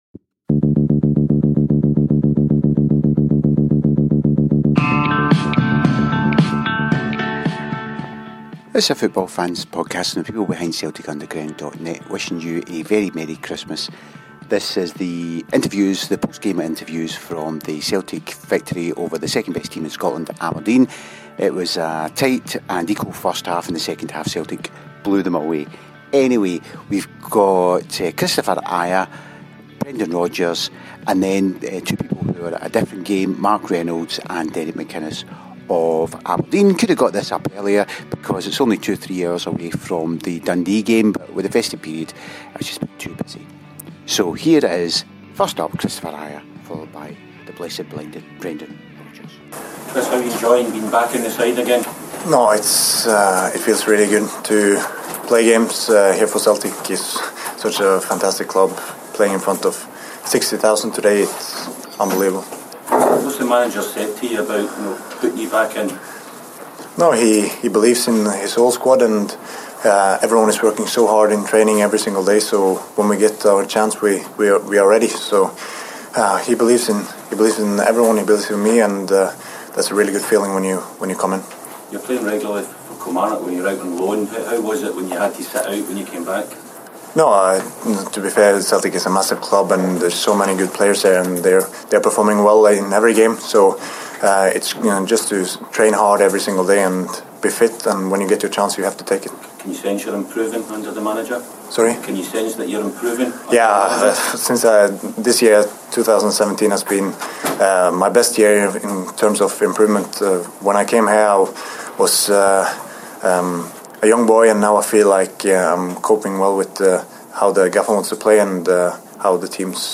After the game Kristopher Ajer, Brendan Rodgers, Mark Reynolds and Derek McInnes spoke to the press.